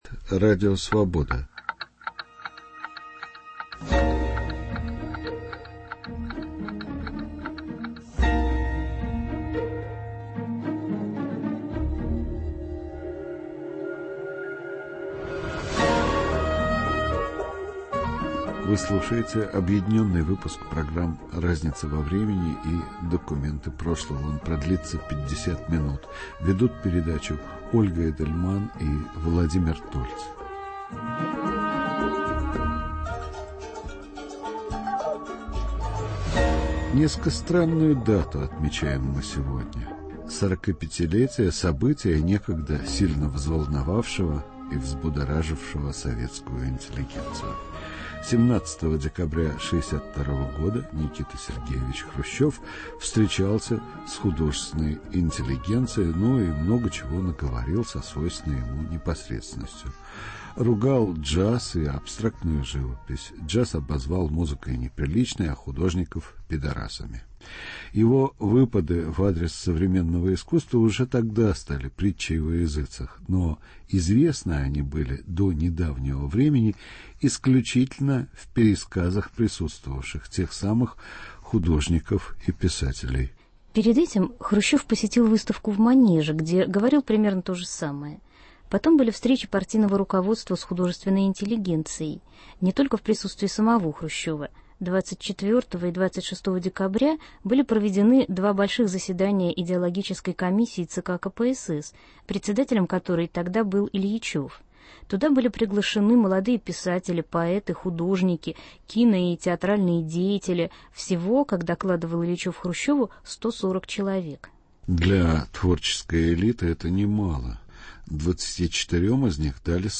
Объединенный 50-минутный выпуск программ РАЗНИЦА ВО ВРЕМЕНИ и ДОКУМЕНТЫ ПРОШЛОГО посвящен 45-летию встречи руководства КПСС и советской культурной элитой.